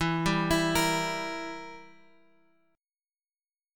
Edim chord {x 7 5 x 5 6} chord
E-Diminished-E-x,7,5,x,5,6-8.m4a